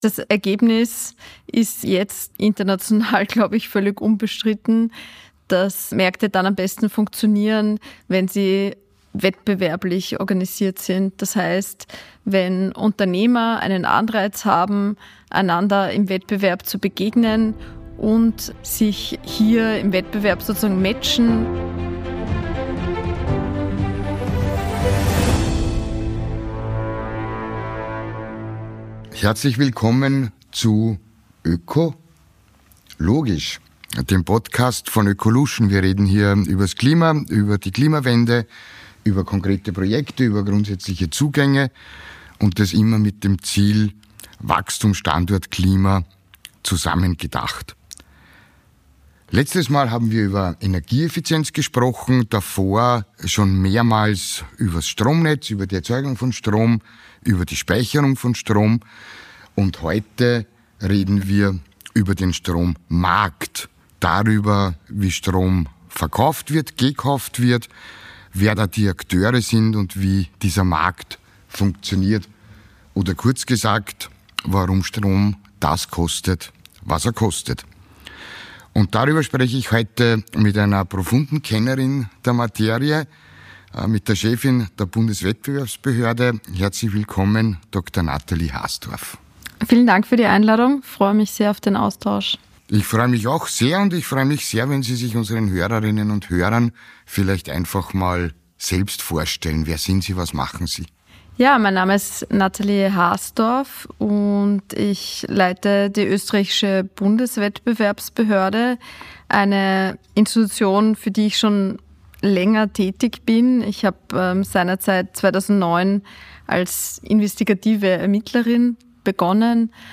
Interview mit Natalie Harsdorf, Generaldirektorin der Bundeswettbewerbsbehörde.